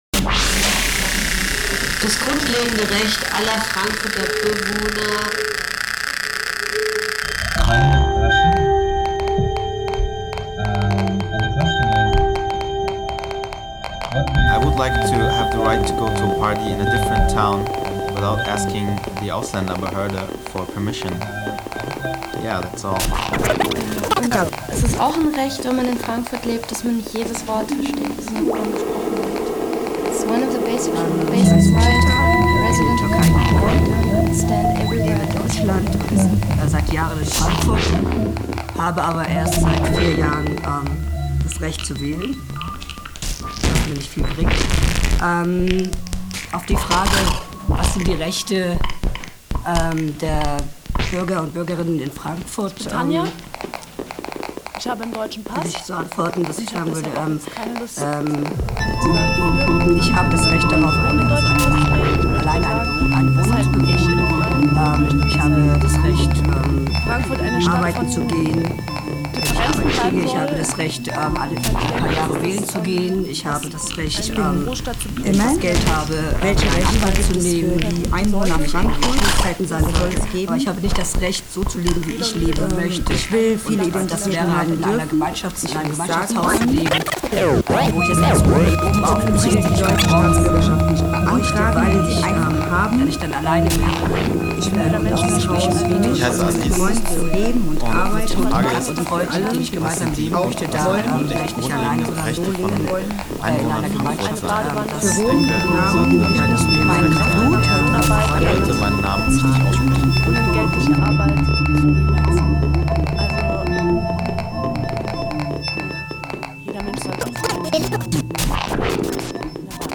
soundscapes, minimal-house, and electro-beats